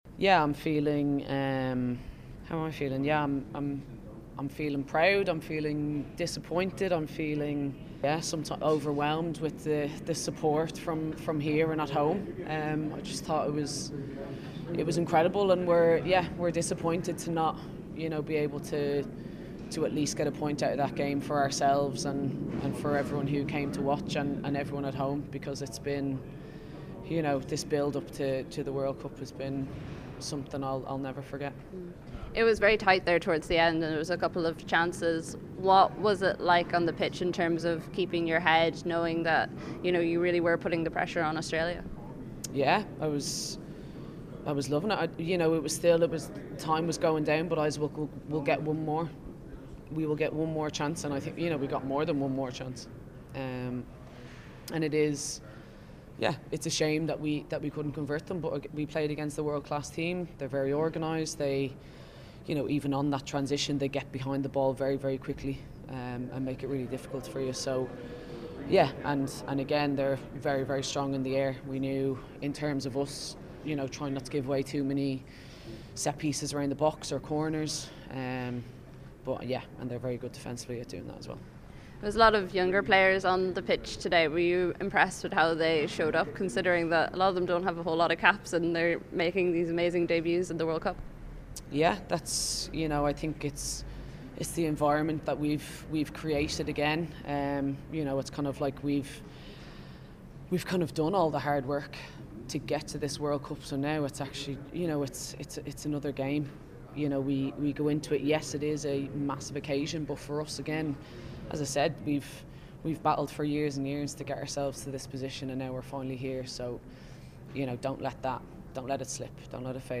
Listen: Blessington's Louise Quinn Speaking After Australia Defeat